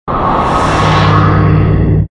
cloak_rh_fighter.wav